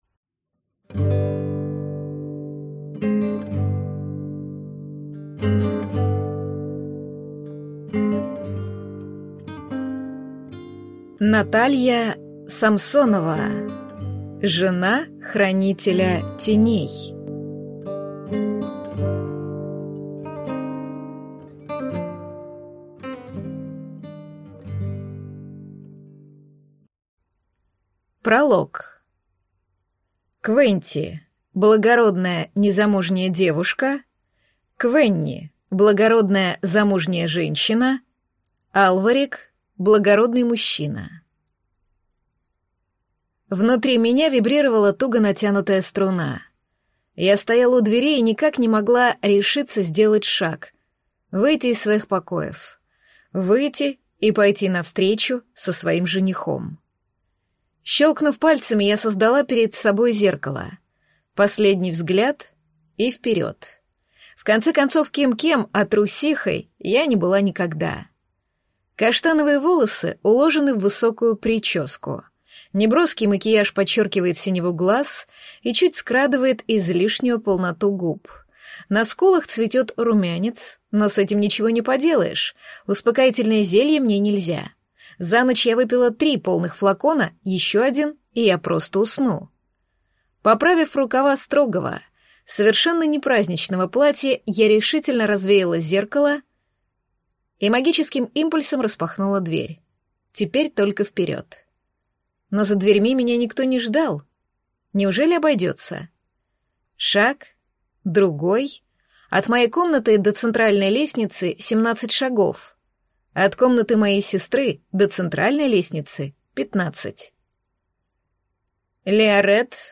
Аудиокнига Жена Хранителя Теней | Библиотека аудиокниг